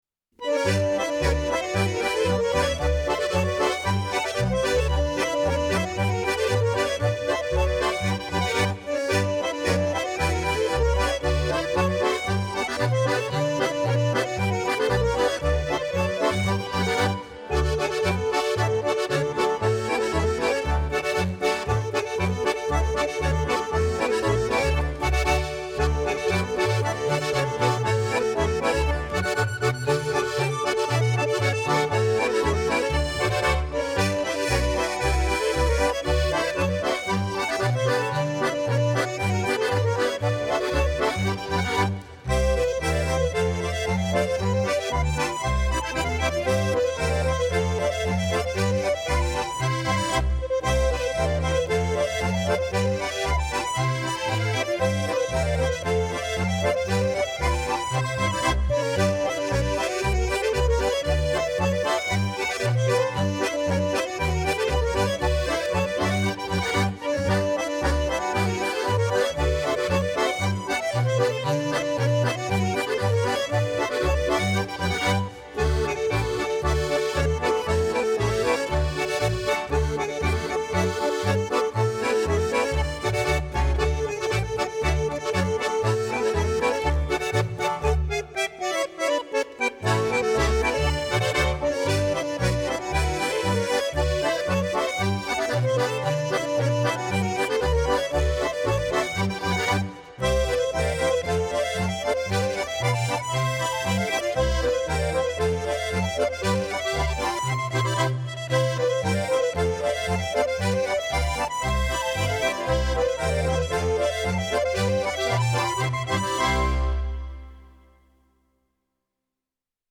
die etwas andere Volksmusik